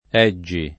Eggi [ $JJ i ]